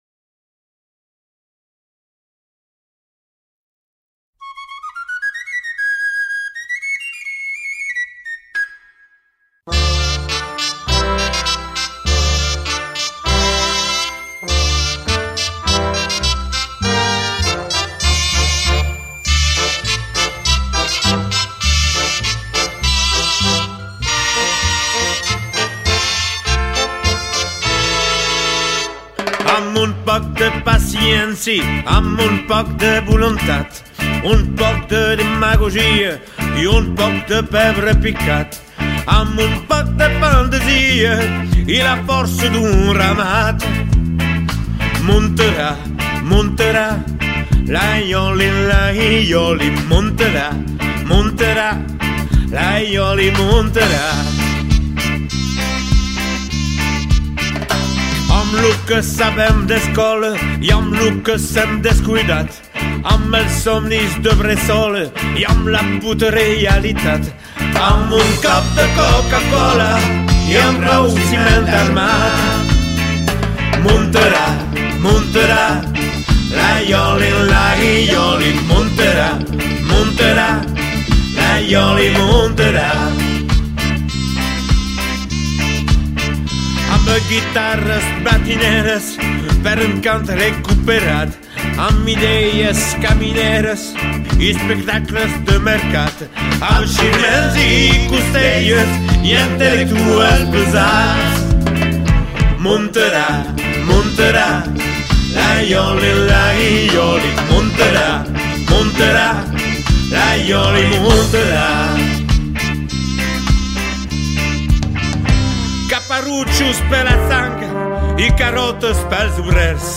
versió sardanista